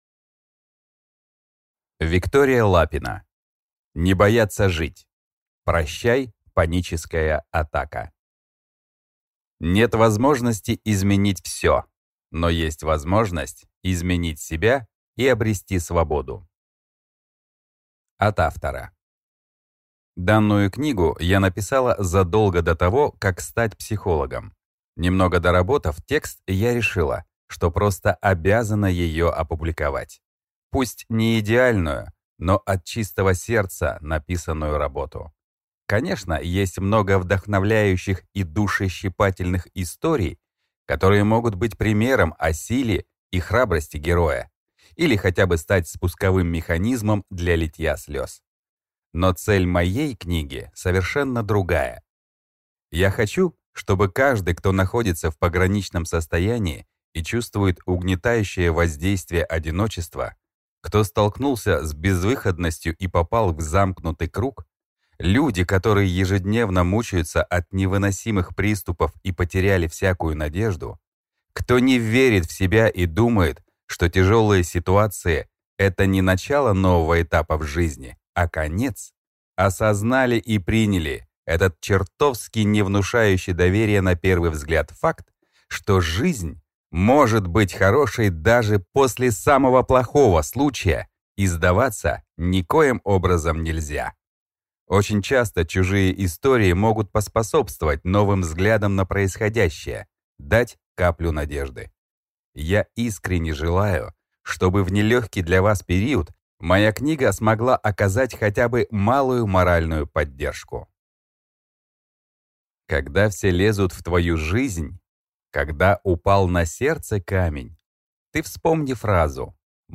Аудиокнига Не бояться жить. Прощай, паническая атака | Библиотека аудиокниг